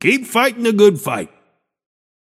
Shopkeeper voice line - Keep fightin‘ the good fight.